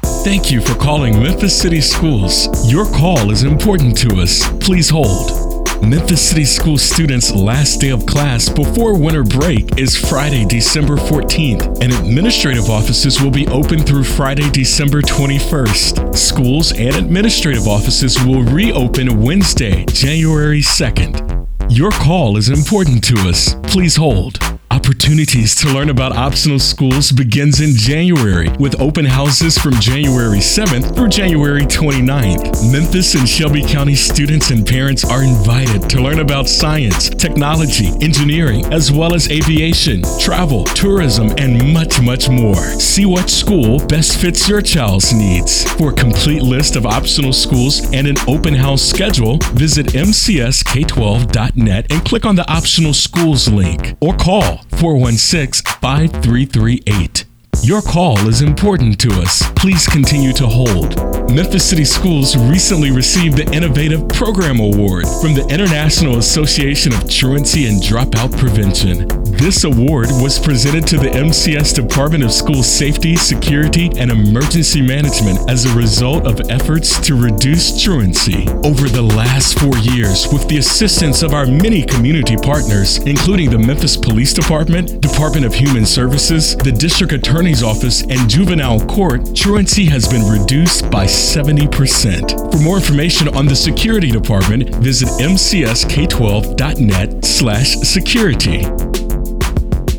On Hold Messaging: Memphis City Schools District: